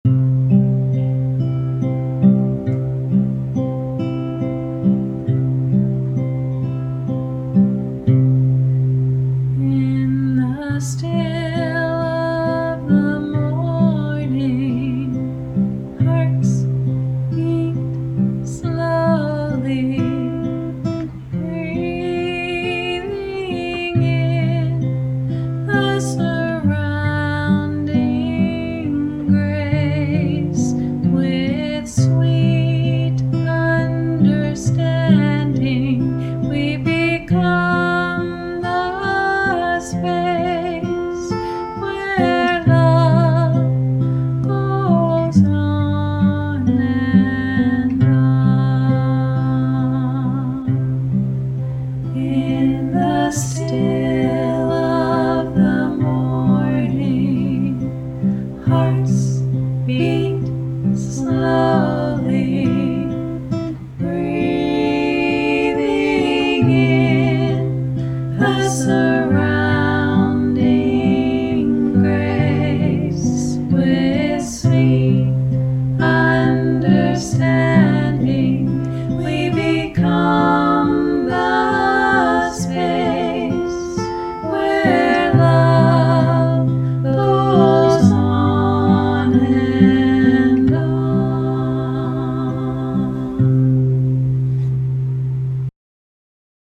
In-the-Still-of-the-Morning-in-C-we-version-all-parts.mp3